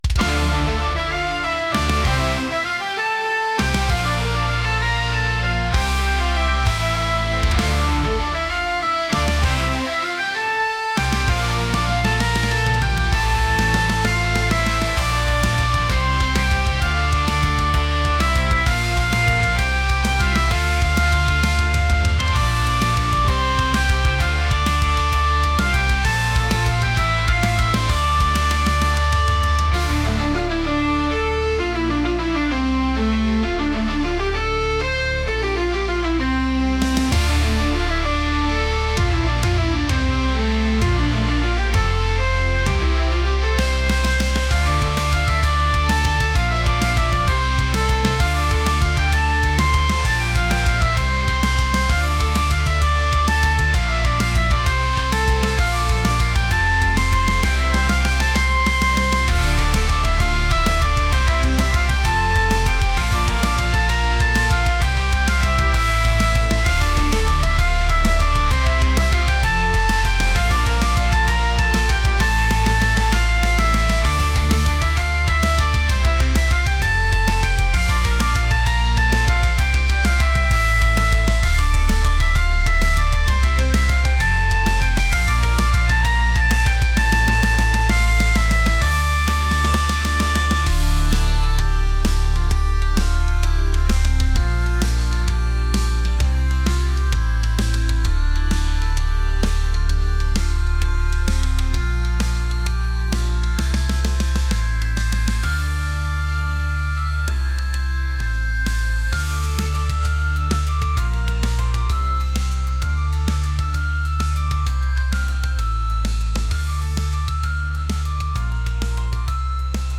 metal | heavy